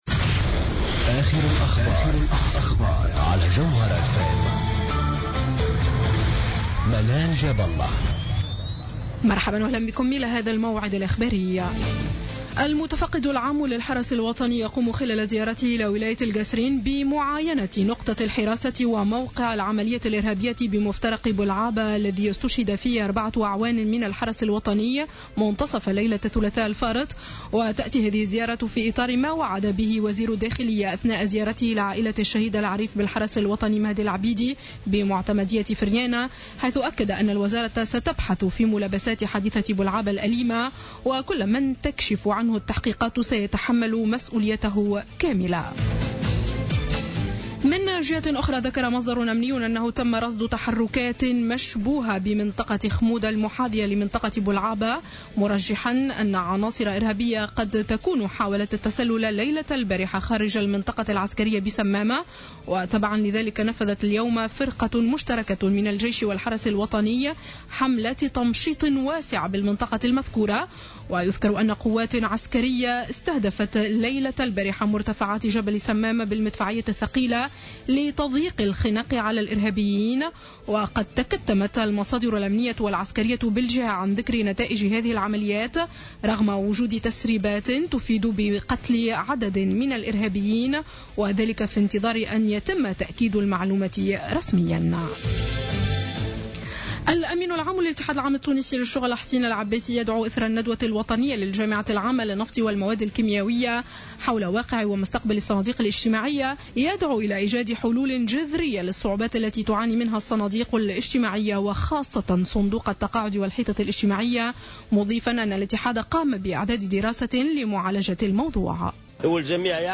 نشرة أخبار السابعة مساء ليوم الخميس 19 فيفري 2015